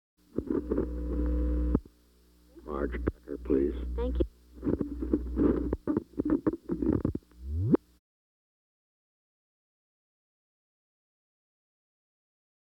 Secret White House Tapes
Location: White House Telephone
The President talked with the White House operator.